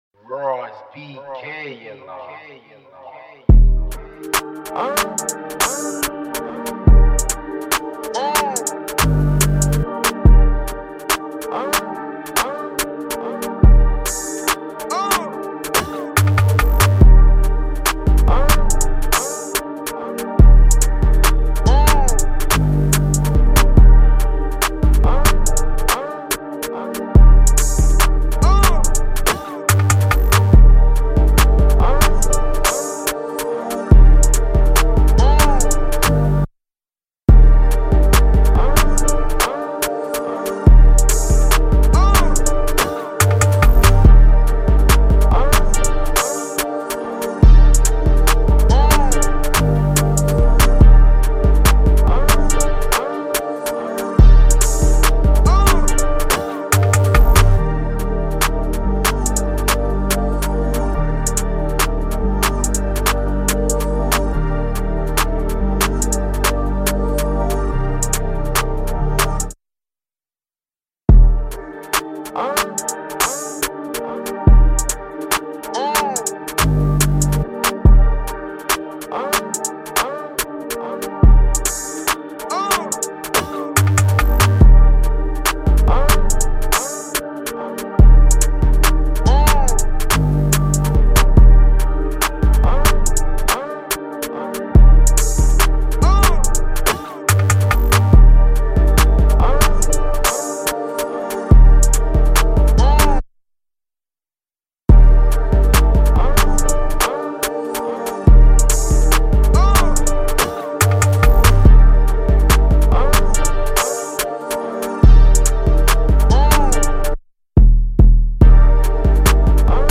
UK Drill Instrumentals